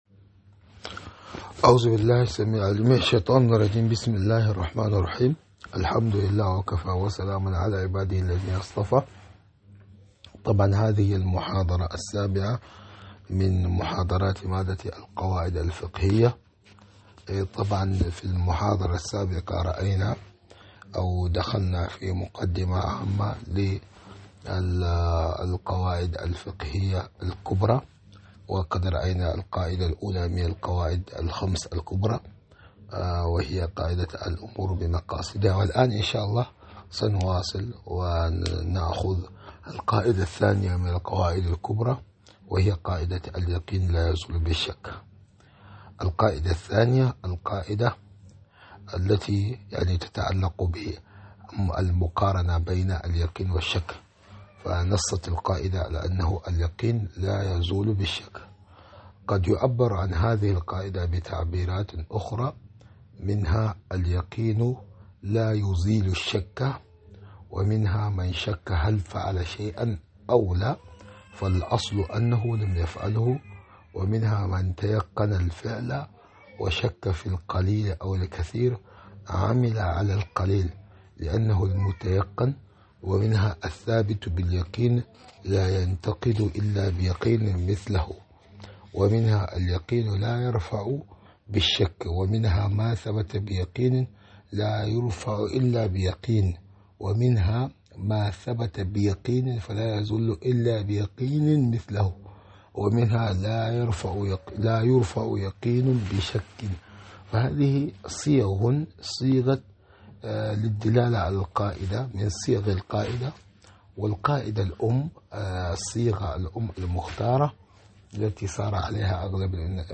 محاضرة مادة القواعد الفقهية 007